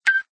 tone_12.ogg